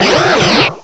cry_not_skrelp.aif